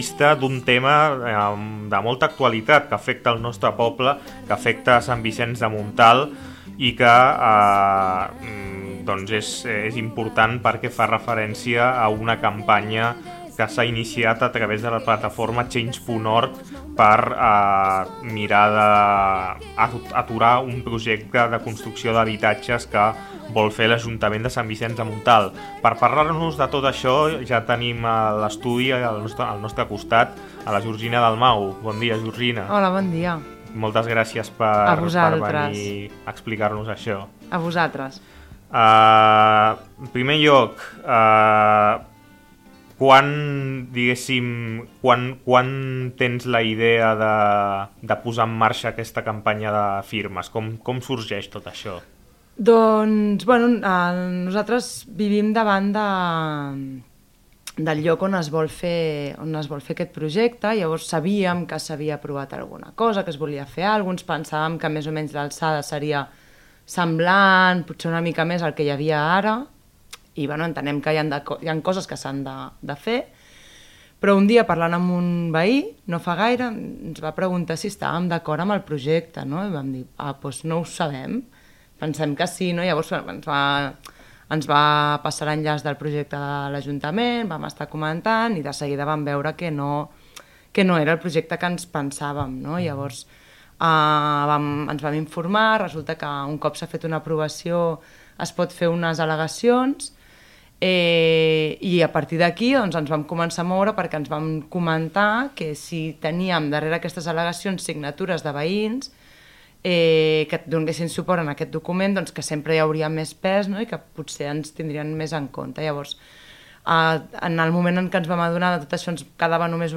Escolta l’entrevista a Ràdio Sanvi feta a la plataforma Stop Engany SVM sobre el projecte que vol impulsar l’ajuntament amb el qual no estem gens d’acord.
entrevista-stops-pisos-svm.mp3